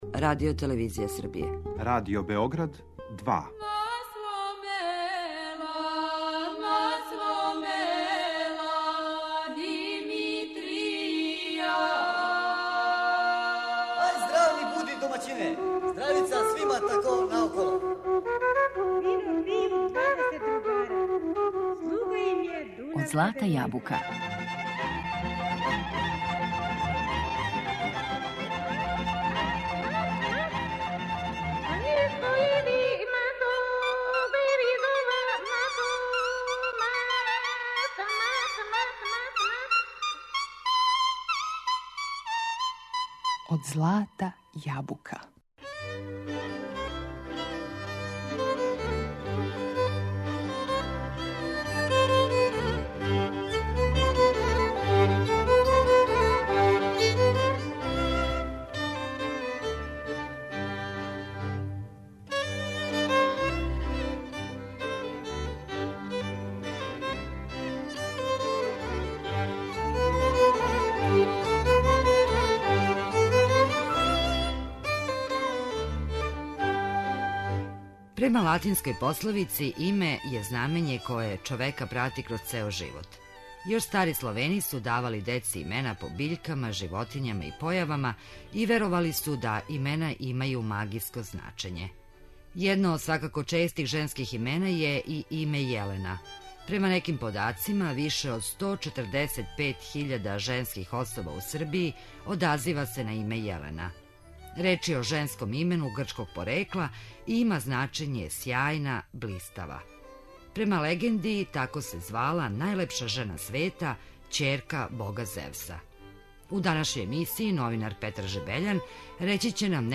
Грчког је порекла и има значење сјајна, блистава, а слушаћемо и песме у којима је ово лепо име испевано.